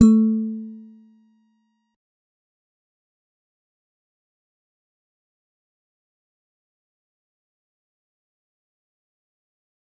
G_Musicbox-A3-mf.wav